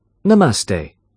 namaste-British-English-pronunciation.mp3